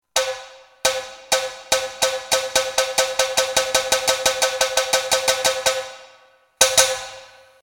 場面転換・オープニング・エンディング
開始の合図１